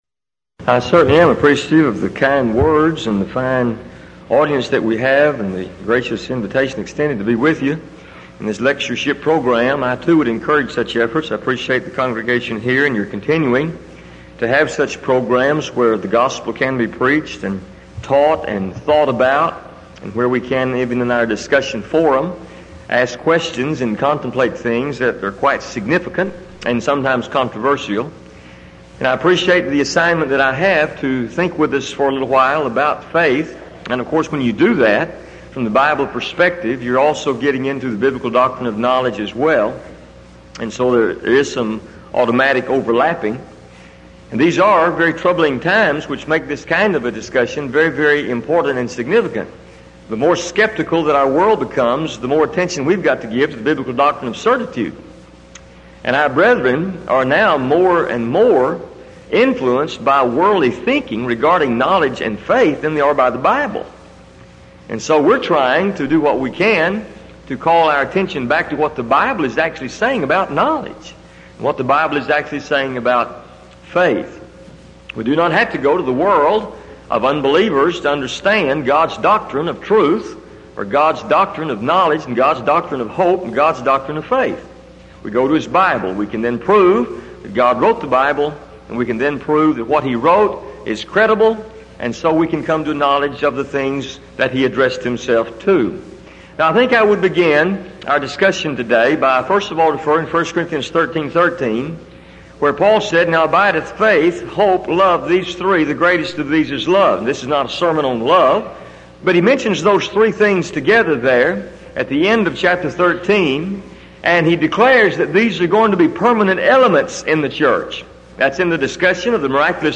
Event: 1989 Denton Lectures Theme/Title: Studies In The Book Of II Corinthians